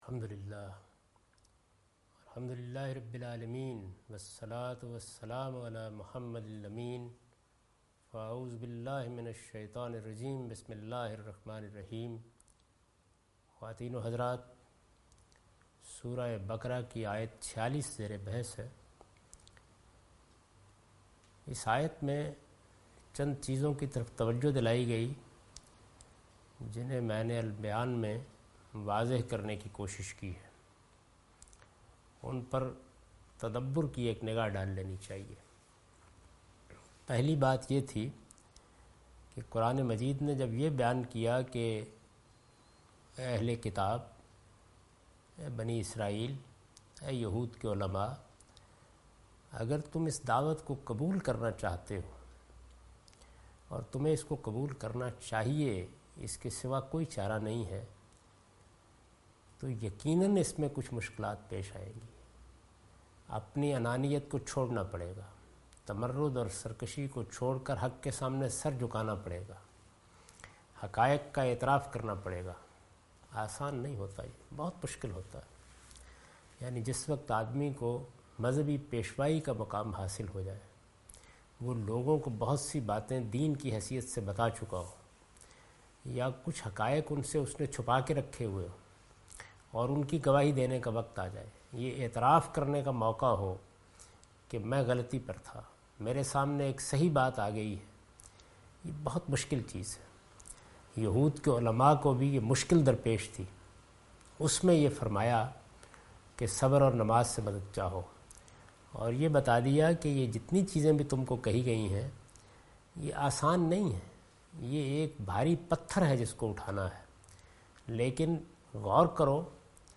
Surah Al-Baqarah - A lecture of Tafseer-ul-Quran – Al-Bayan by Javed Ahmad Ghamidi. Commentary and explanation of verse 47,48,49,50,51 and 52 (Lecture recorded on 23rd May 2013).